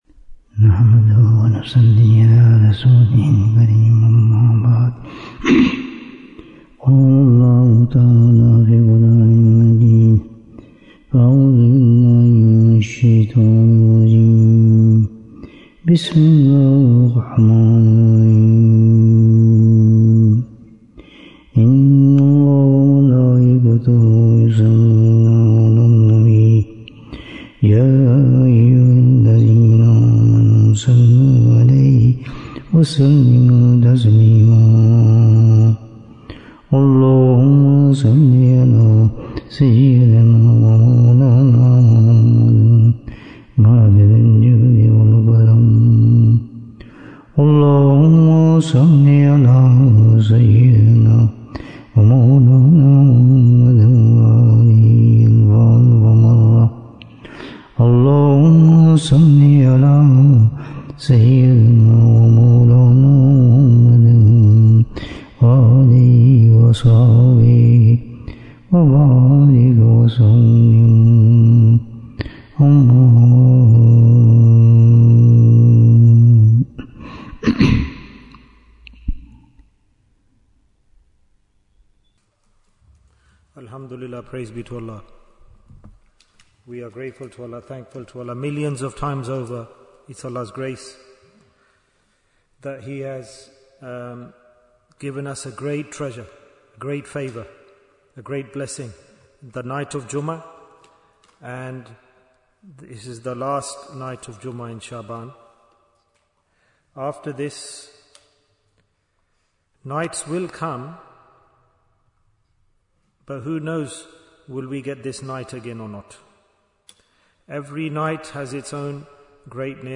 The Dhikr of Allah is Great Bayan, 88 minutes12th February, 2026